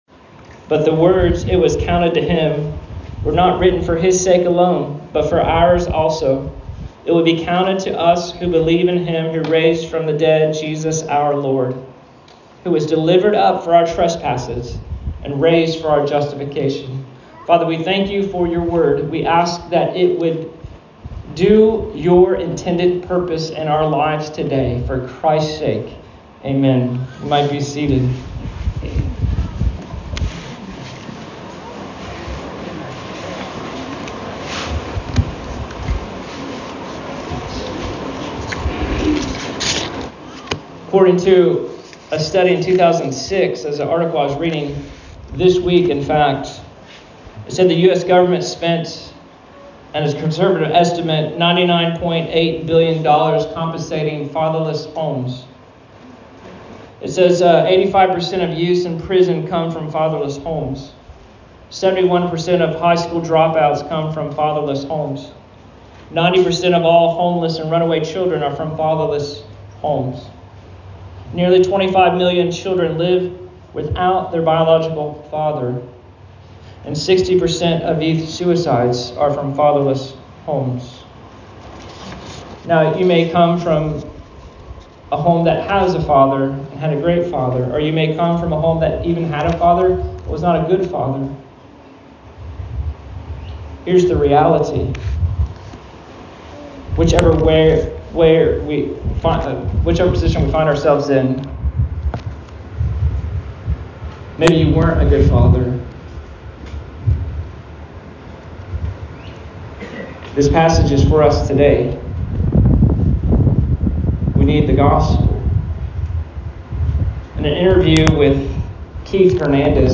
Sermons | Christ Community Church